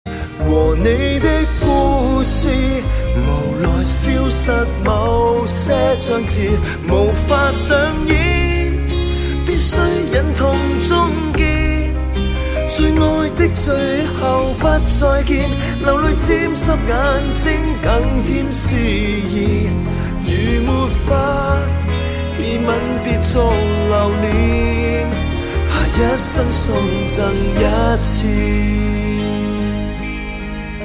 M4R铃声, MP3铃声, 华语歌曲 88 首发日期：2018-05-15 17:15 星期二